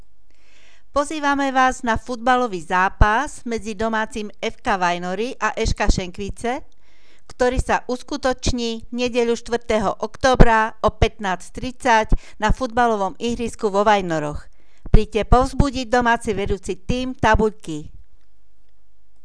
Hlásenie miestneho rozhlasu (Prvá streda so starostom, FK Vajnory vs. ŠK Šenkvice)